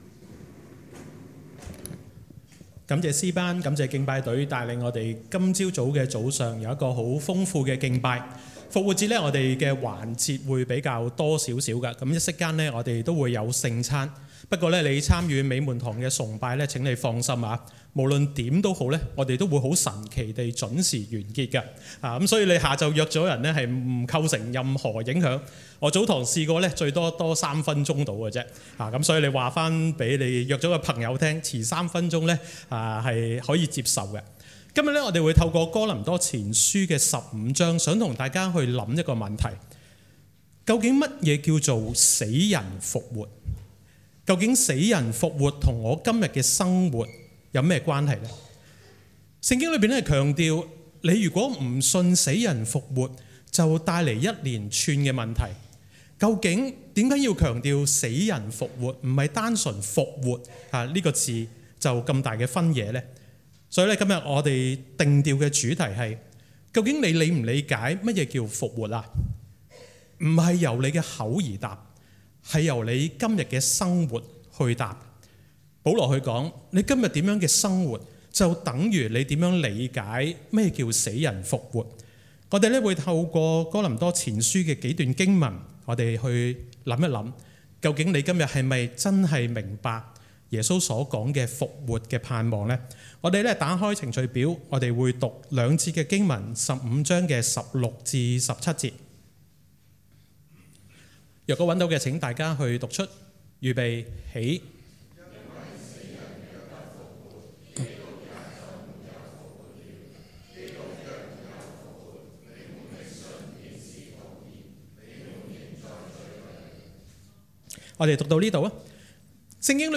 講道類別 : 主日崇拜 經文章節 : 哥林多前書 15 : 16 - 17，32，58